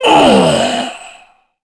Kaulah-Vox_Dead_kr.wav